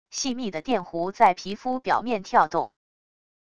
细密的电弧在皮肤表面跳动wav音频